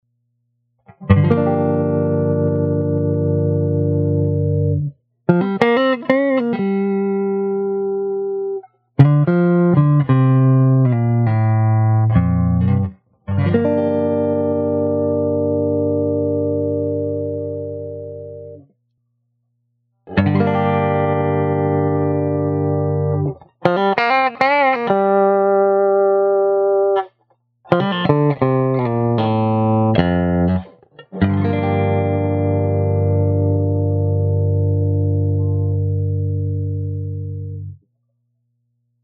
Il body è in tiglio con verniciatura (in poliestere) Sunburst 3-tone: ho scelto il tiglio perchè – sopratutto sulle telecaster (con corde passanti) – mi piace molto come risuona e la pasta sonora che si genera.
Telecaster Custom Relic
(amp: HIWATT T40 HD, cab 2×12 FANE F70+A60, mic AKG dinamico)
Pickup Neck vs Pickup Bridge
TL_WM_neck_bridge.mp3